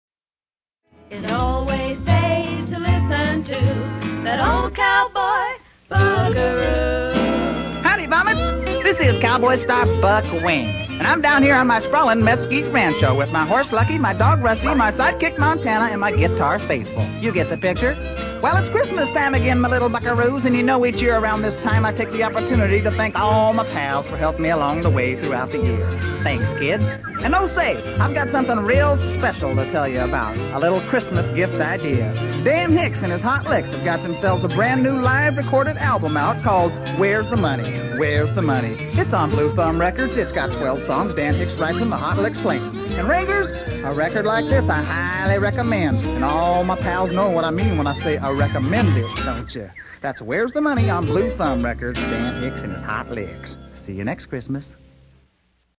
radio ad